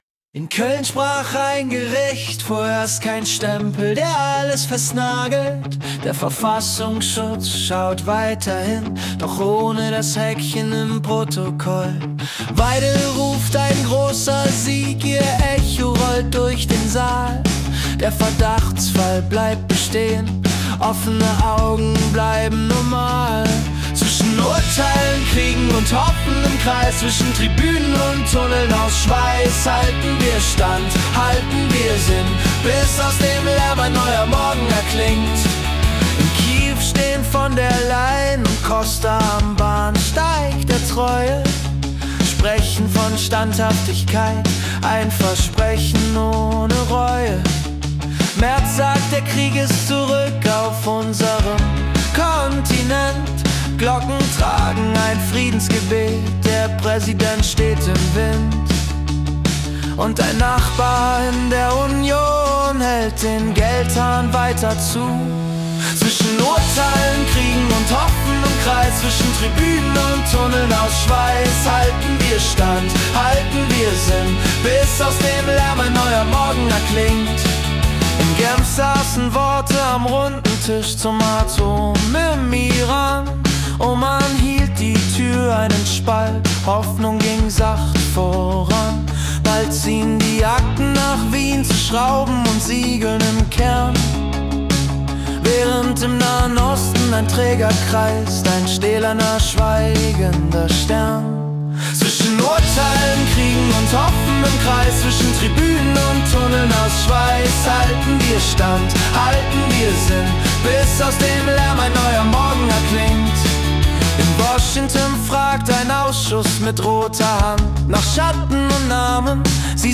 Die Nachrichten vom 27. Februar 2026 als Singer-Songwriter-Song interpretiert.